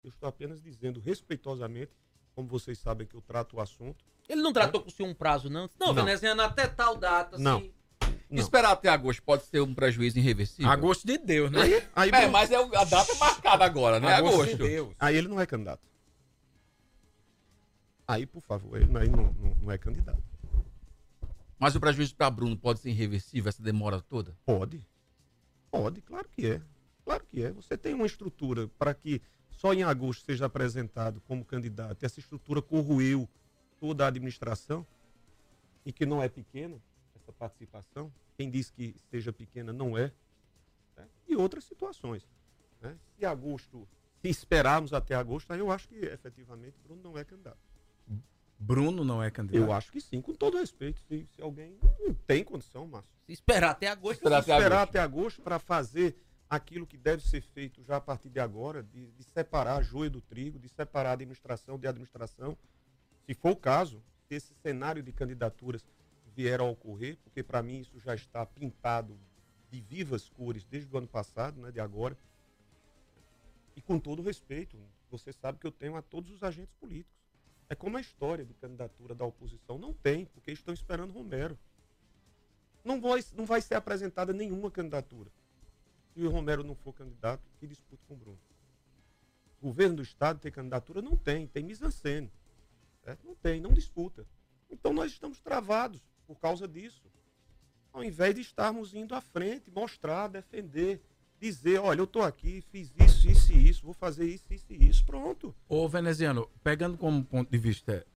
Para o emedebista, Romero tem sabotado a gestão de Bruno e travou o processo eleitoral em Campina Grande. As declarações foram dadas à Arapuan FM Campina Grande.